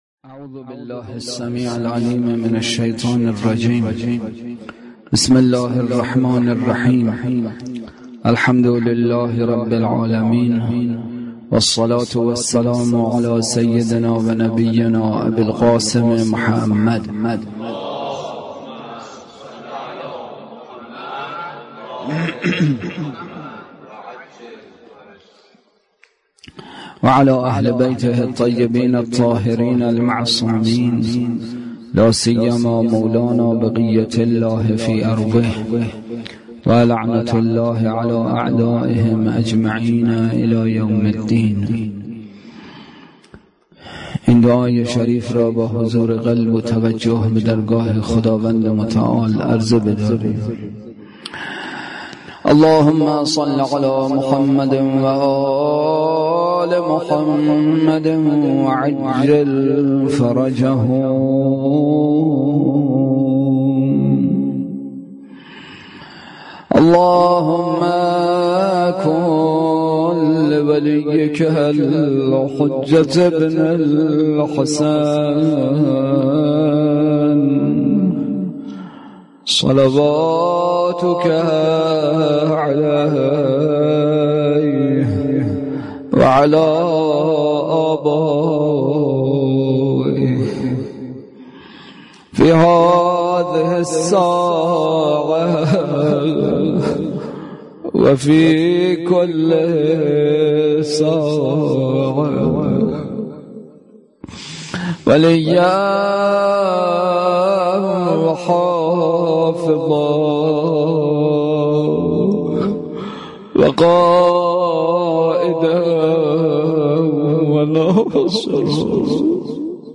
بحث اصلی: شرح زیارت عاشورا؛ شبهات مربوط به لعن روضه: حضرت زهرا(علیهاالسلام)